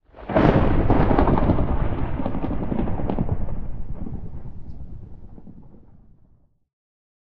Minecraft Version Minecraft Version snapshot Latest Release | Latest Snapshot snapshot / assets / minecraft / sounds / ambient / weather / thunder2.ogg Compare With Compare With Latest Release | Latest Snapshot
thunder2.ogg